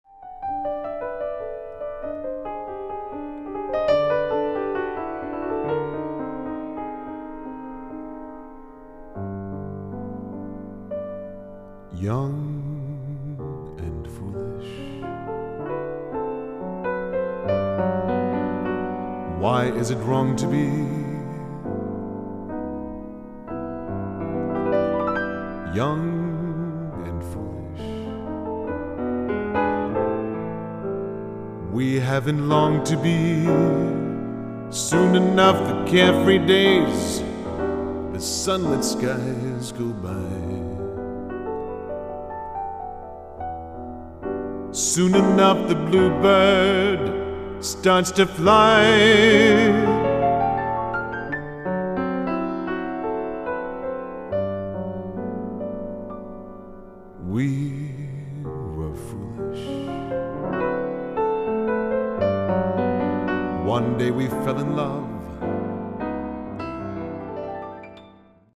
piano/vocal